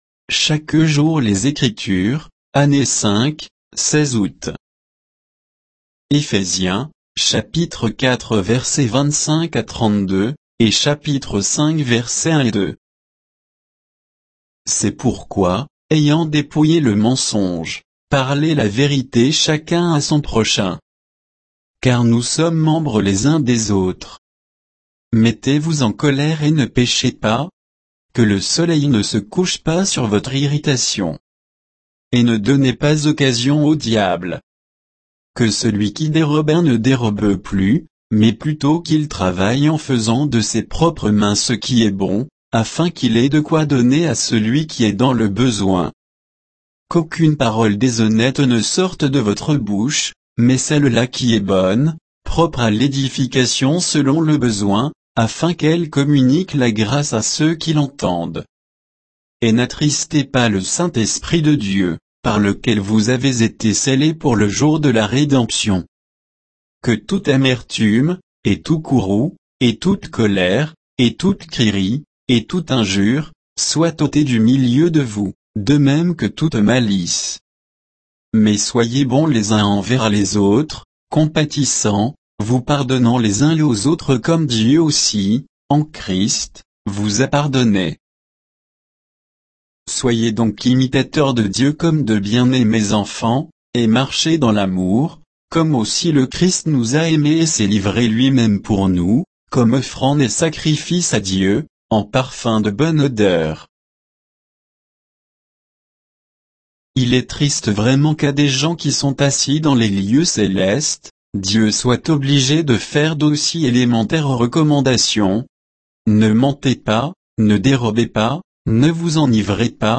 Méditation quoditienne de Chaque jour les Écritures sur Éphésiens 4, 25 à 5, 2